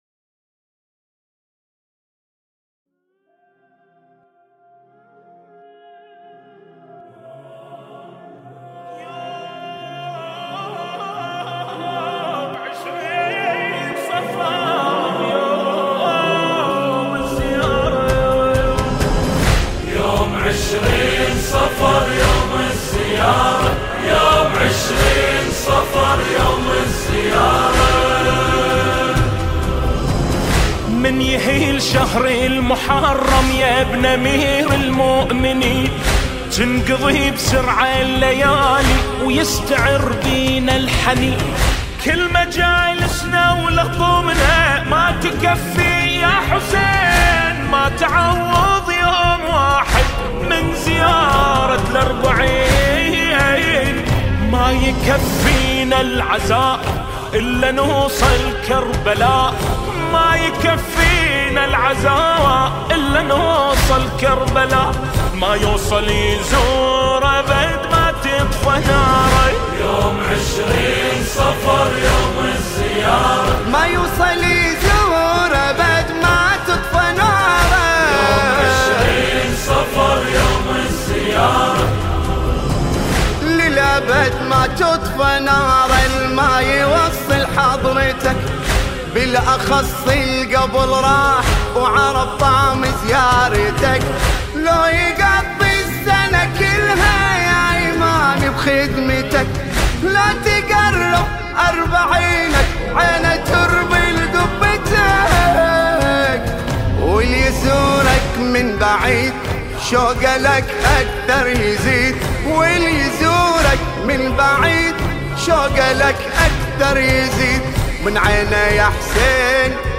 | أداء | الرواديد